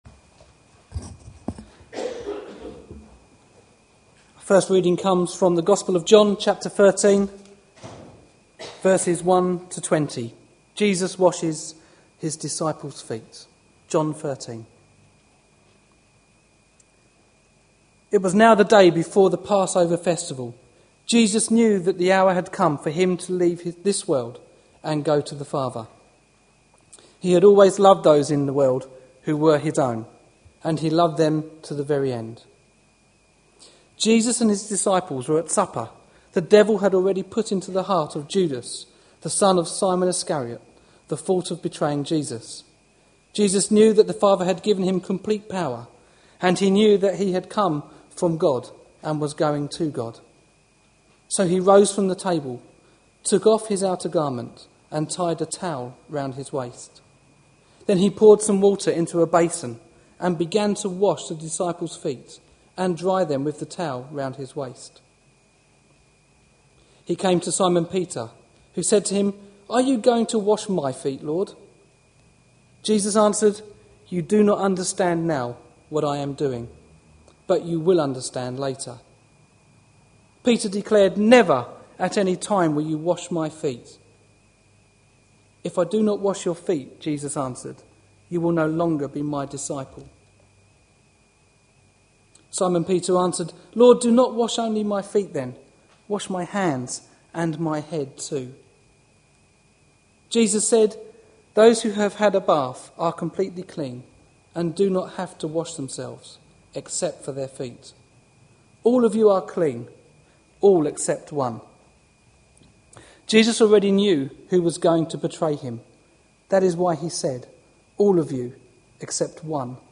A sermon preached on 26th February, 2012, as part of our Looking For Love (6pm Series) series.